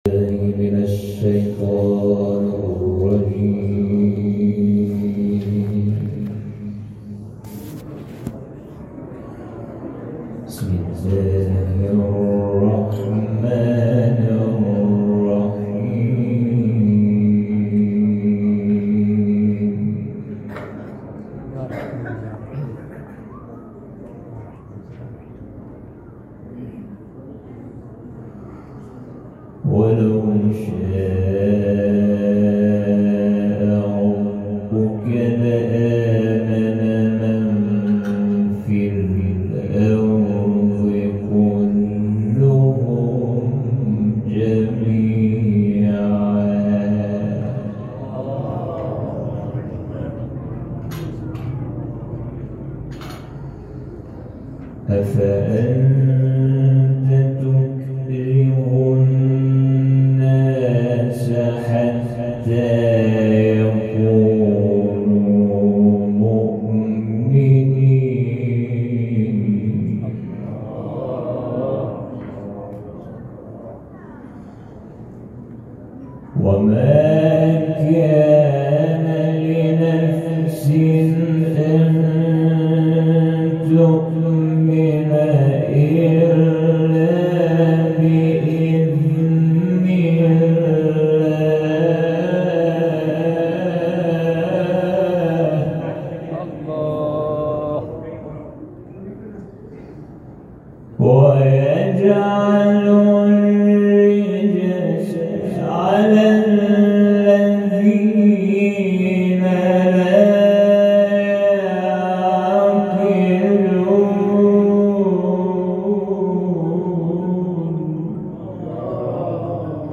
تلاوت
اختلاف قرائت : حفص از عاصم – ورش از نافع مدنی